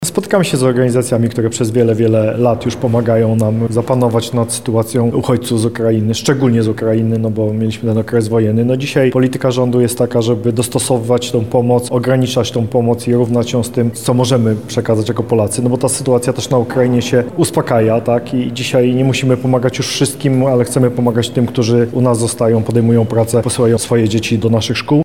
Andrzej Maj – mówi Wicemarszałek Andrzej Maj.
andrzej-maj.mp3